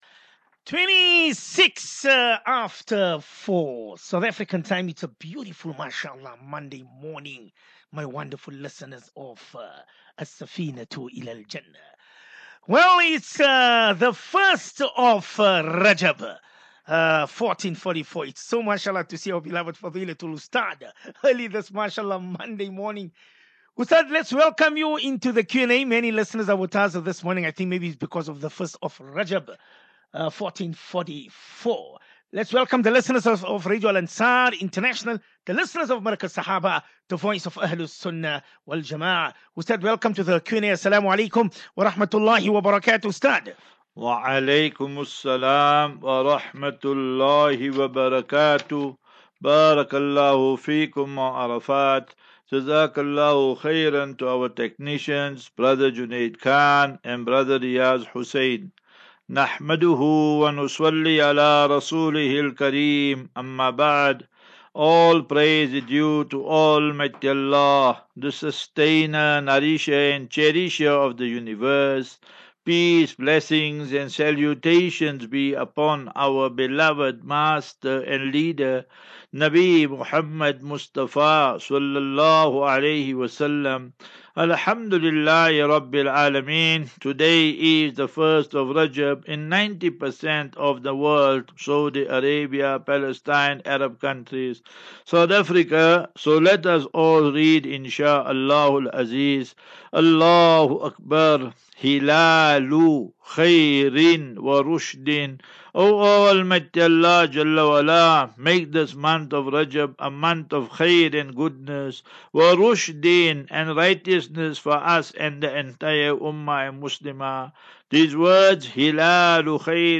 View Promo Continue Install As Safinatu Ilal Jannah Naseeha and Q and A 23 Jan 23 Jan 23- Assafinatu-Illal Jannah 38 MIN Download